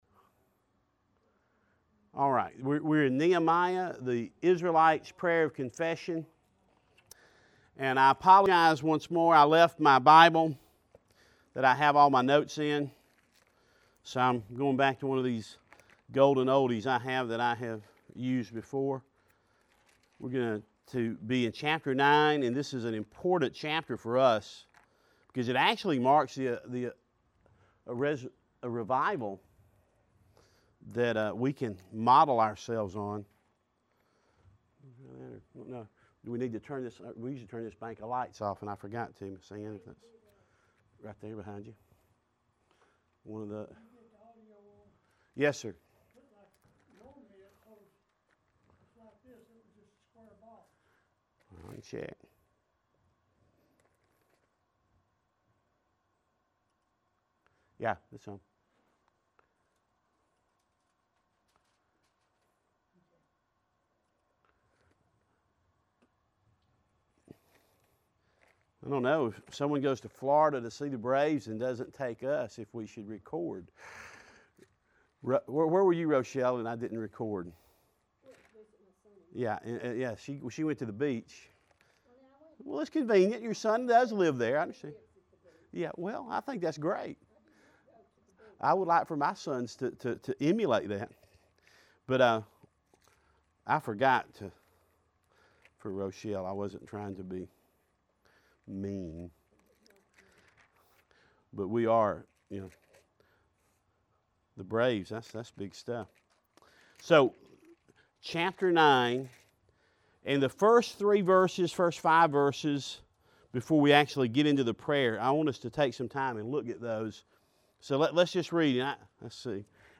Lesson Nine